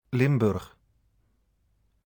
Limburg (Dutch pronunciation: [ˈlɪmbʏr(ə)x]